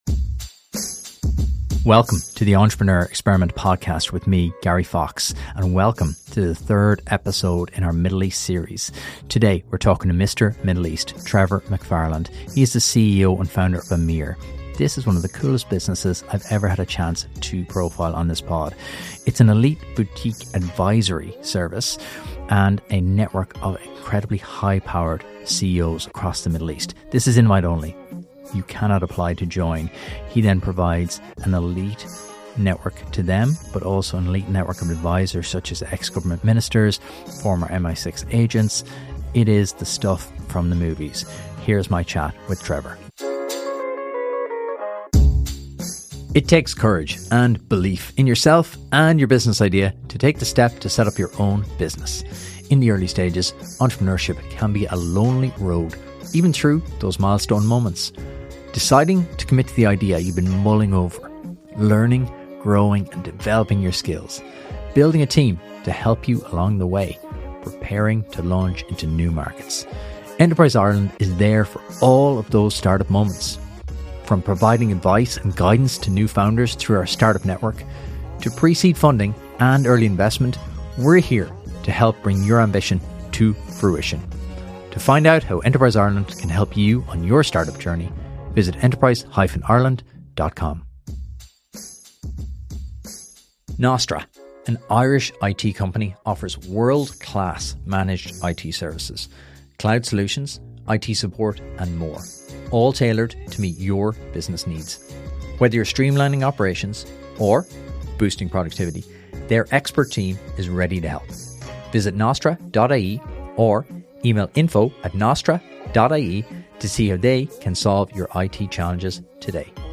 In this special Dubai-recorded edition of The Entrepreneur Experiment